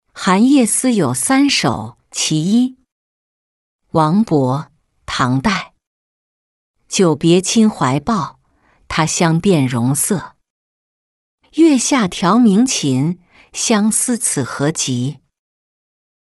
别房太尉墓-音频朗读